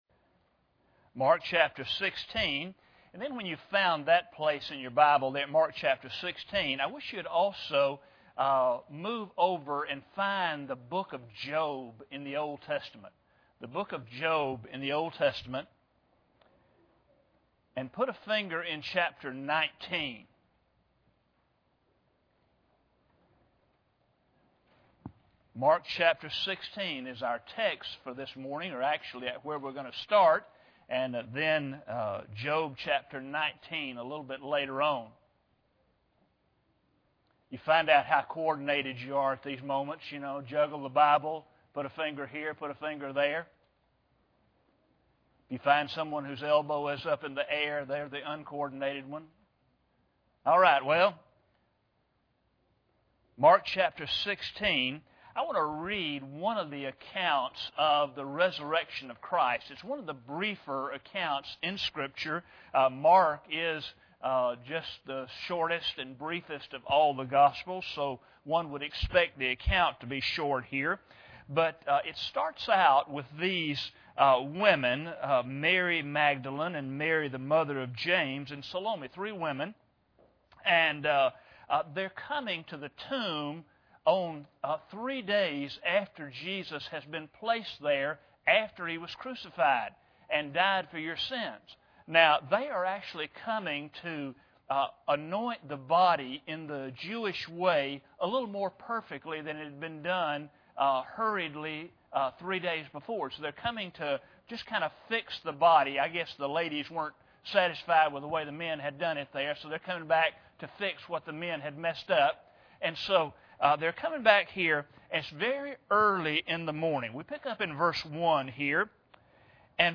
Mark 16:1-9 Service Type: Sunday Morning Bible Text